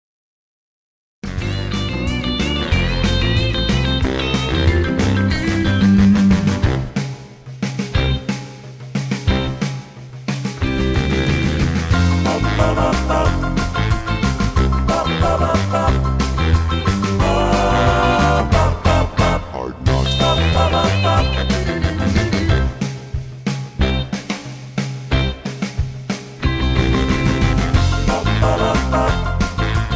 backing tracks, rock